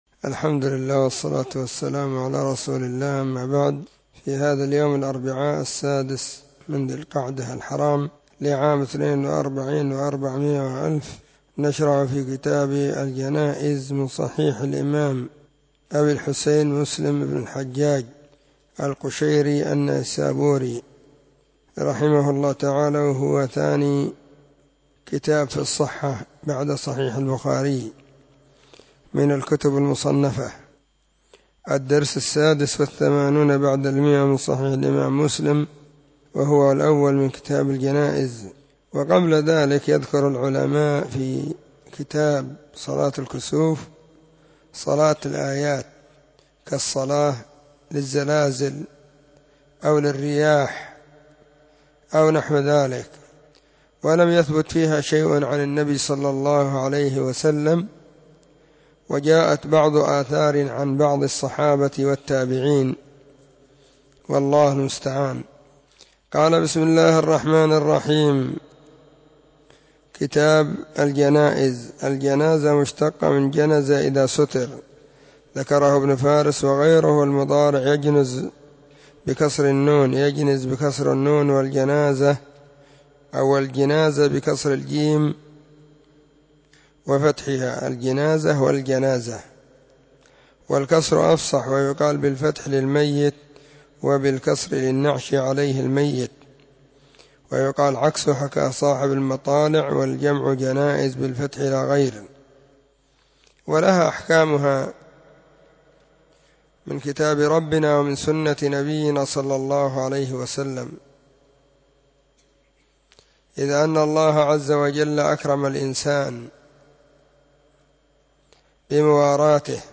📢 مسجد الصحابة – بالغيضة – المهرة، اليمن حرسها الله.
كتاب-الجنائز-الدرس-1.mp3